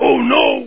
粗犷男声oh no 音效_人物音效音效配乐_免费素材下载_提案神器
粗犷男声oh no 音效免费音频素材下载